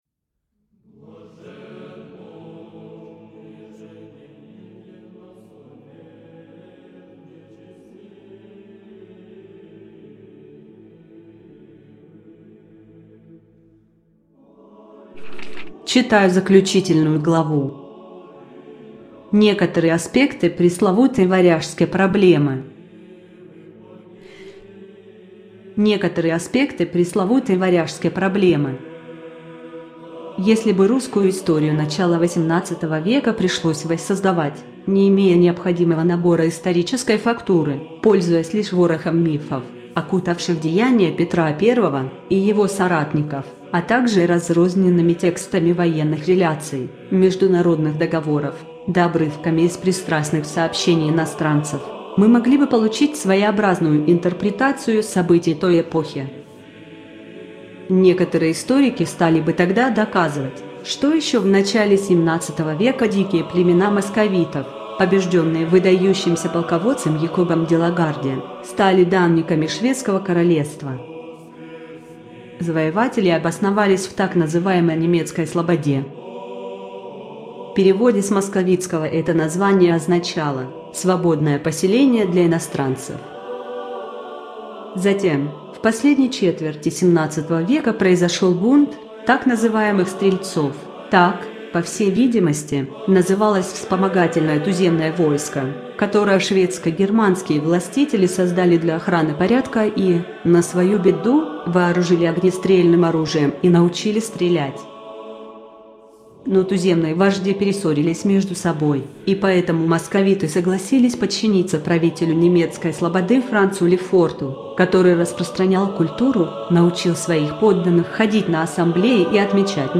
Автор Татищев Василий Никитич из аудиокниги "Иоакимовская летопись".